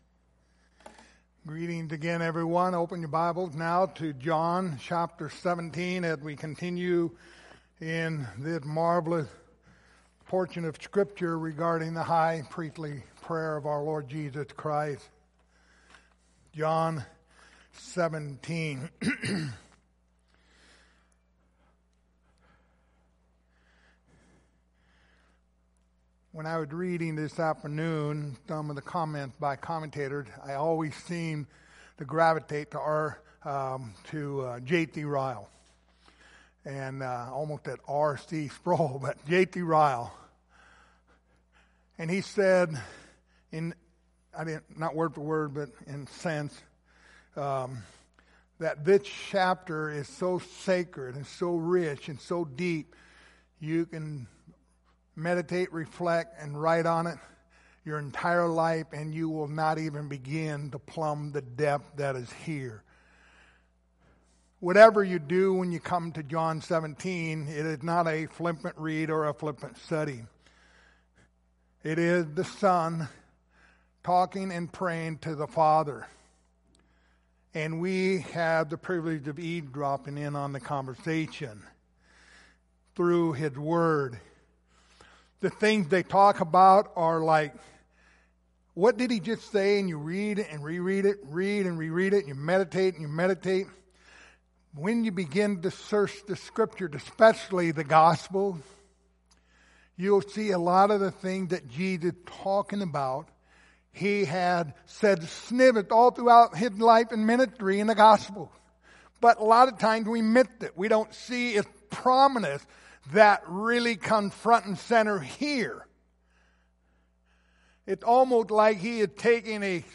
Service Type: Lord's Supper